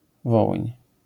Polish : Wołyń [ˈvɔwɨɲ]